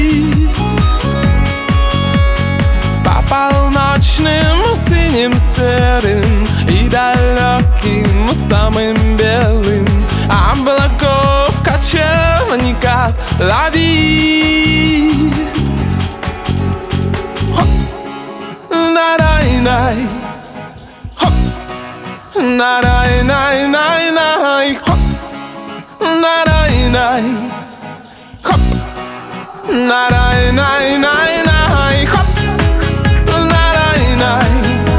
2006-01-01 Жанр: Поп музыка Длительность